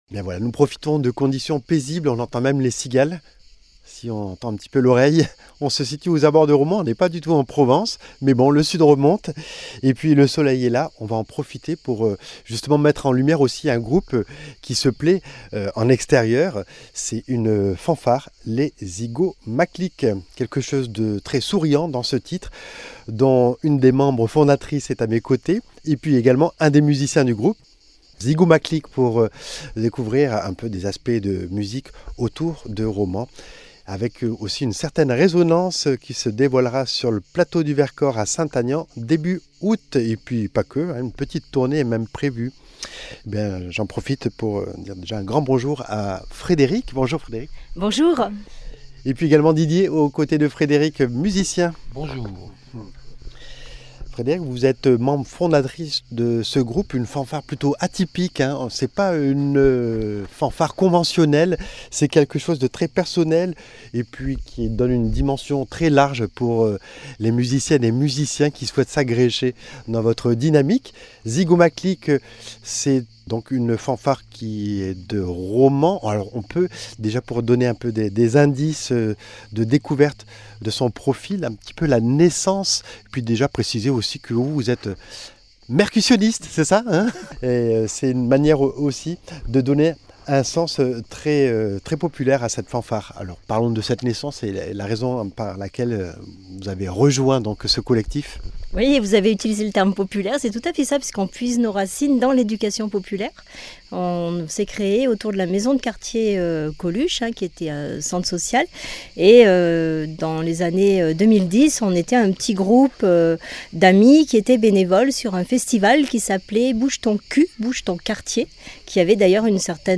Cuivres, percussions, bois, basse profonde et diato s’entremêlent sur des airs de valse, de rock, de funk, de reggae, de chants sur des arrangements en tout genre, probables ou … improbables! »